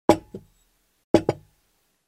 Звуки кулинарии
Посуду расставляют на стол